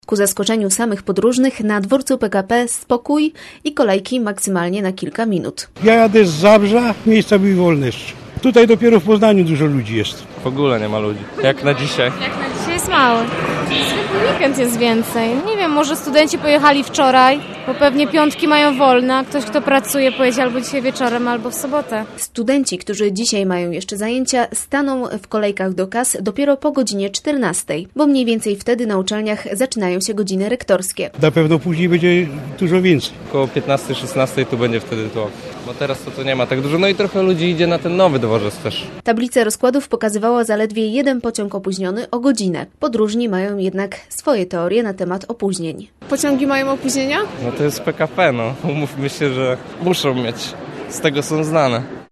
Nie ma tłoku przy kasach i tylko jeden pociąg się spóźnił. Reporterka Radia Merkury wybrała się na dworzec PKP, żeby sprawdzić jak radzą sobie podróżni jadący na Święta pociągiem.